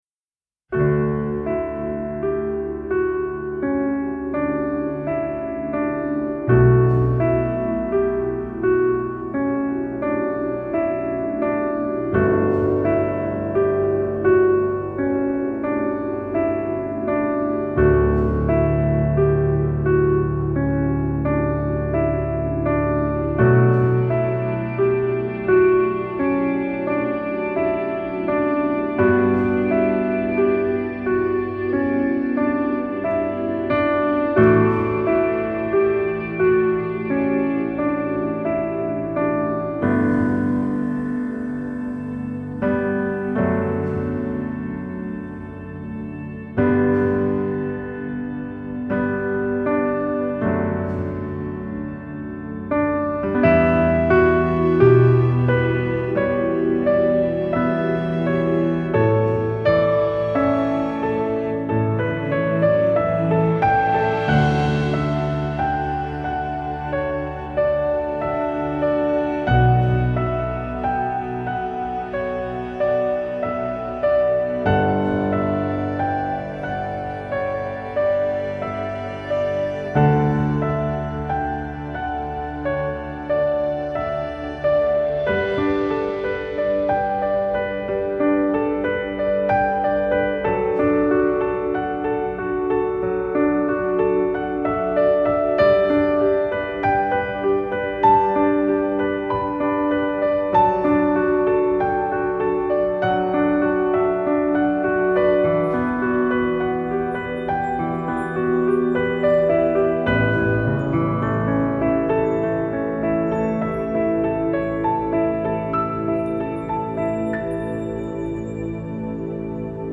FLAC Жанр: New Age, Instrumental, Easy Listening Издание